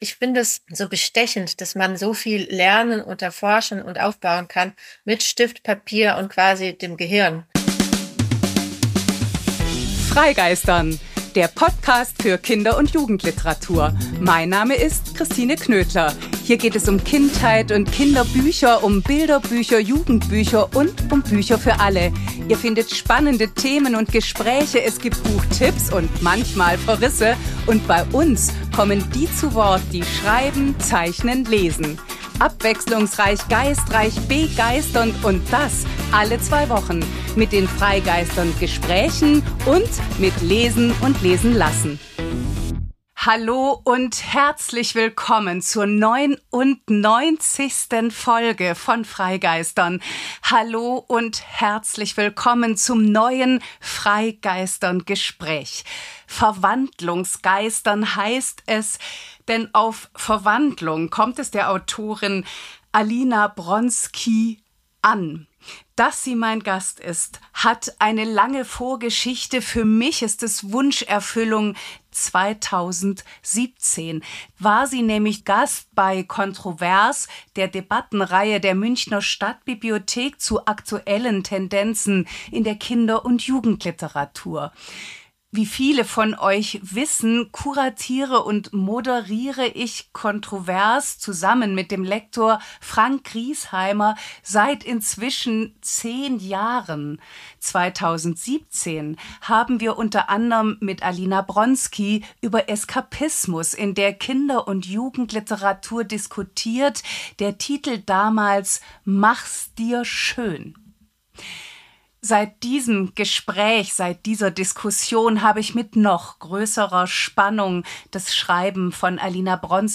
Ein Gespräch mit der Bestsellerautorin Auf Verwandlung kommt es der russisch-deutschen Autorin Alina Bronsky an. Sie selbst ist eine Verwandlungskünstlerin.